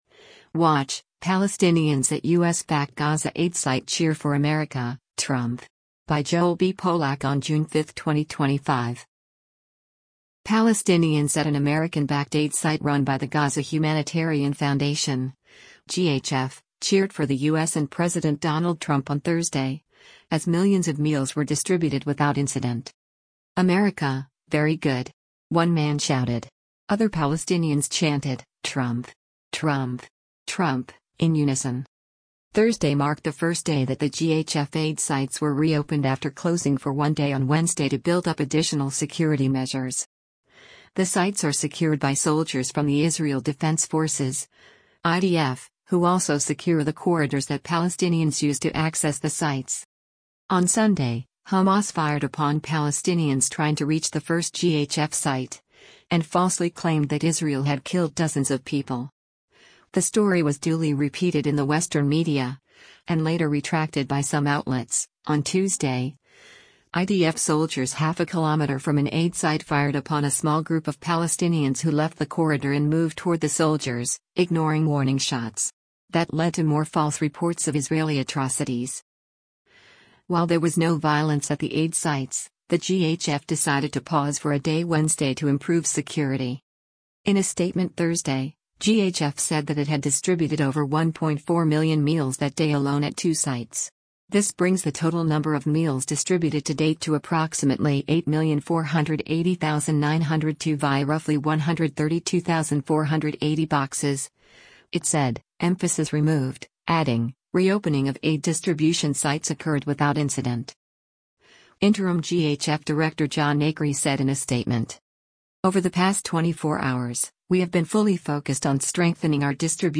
WATCH: Palestinians at U.S.-Backed Gaza Aid Site Cheer for America, Trump
Other Palestinians chanted, “Trump! Trump! Trump!”, in unison.